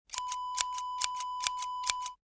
Scissors Snip Wav Sound Effect #13
Description: The sound of a pair of scissors snipping
Properties: 48.000 kHz 16-bit Stereo
A beep sound is embedded in the audio preview file but it is not present in the high resolution downloadable wav file.
Keywords: scissors, snip, snipping, cut, cutting, hair, click, clicking
scissors-snip-preview-13.mp3